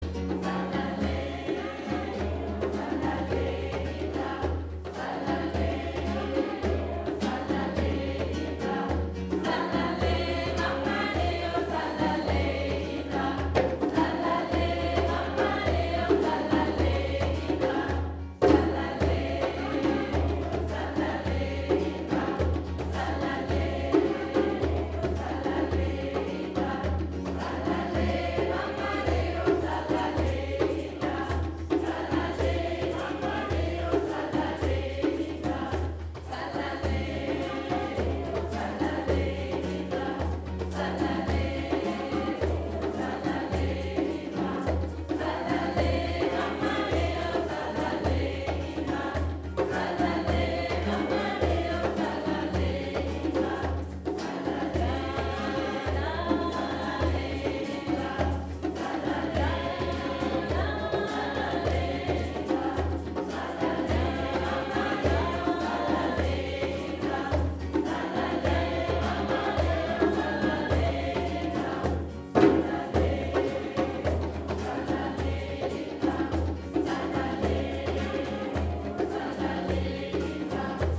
CHANTABEND  "AFRIKA"   MAI 2014
aber auch besinnliche,  afrikanische Chants gesungen, getrommelt
An der Djembe begleitete uns: